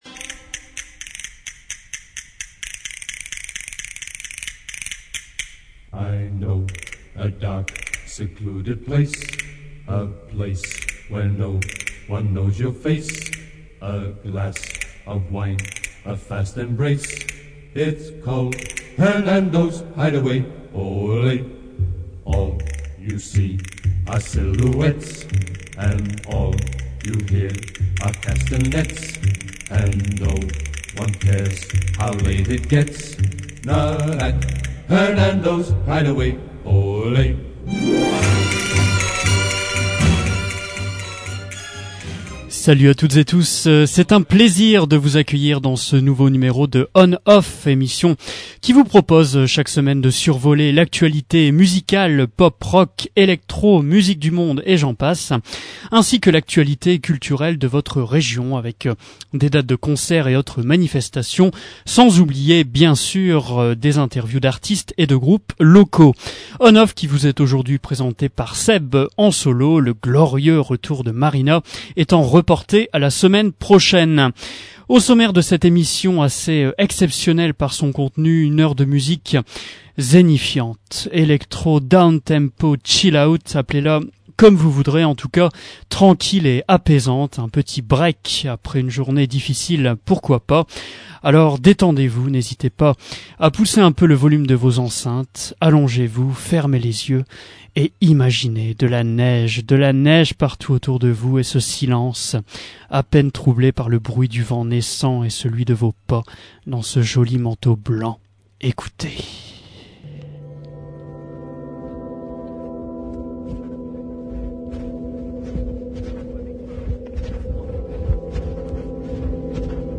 une heure de musique planante pour dcompresser